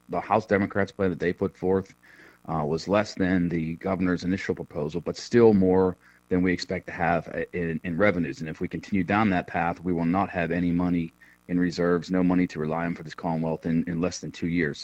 State Representative Jim Struzzi said in an interview that it’s hard to say when a budget will be ready.